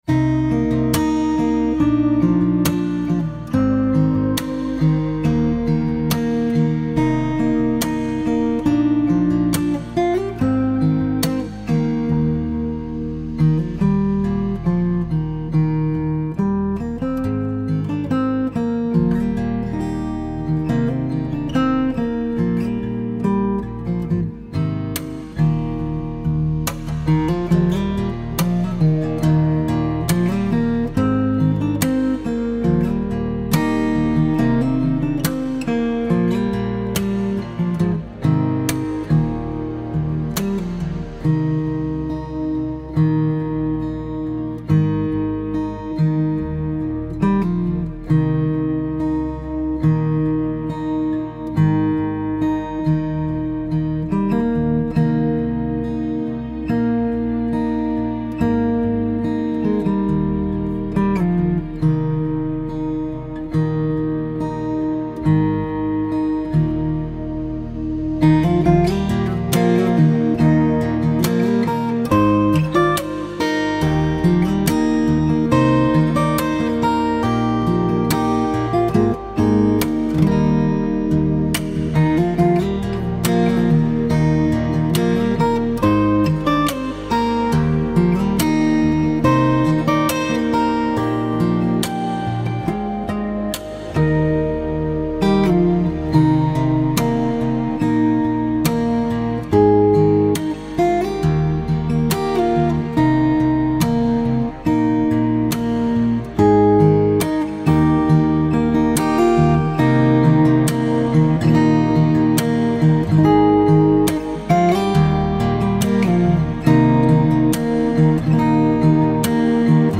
Instrumental: